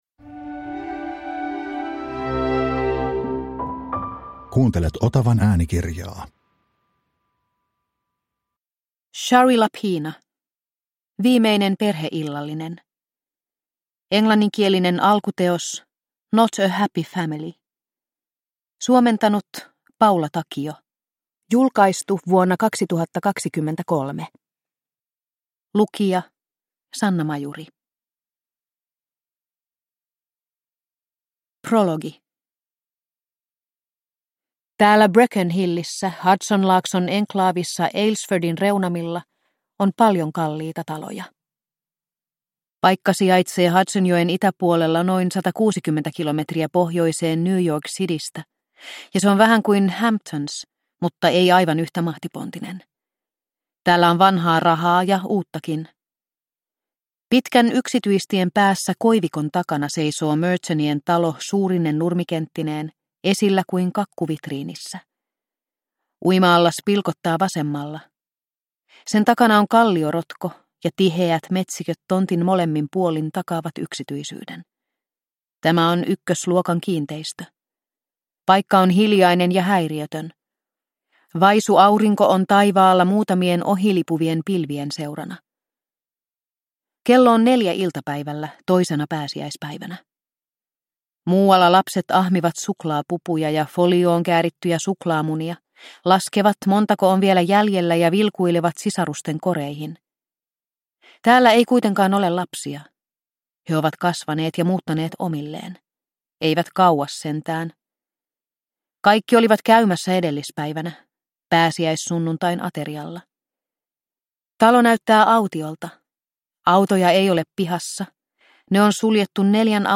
Viimeinen perheillallinen – Ljudbok – Laddas ner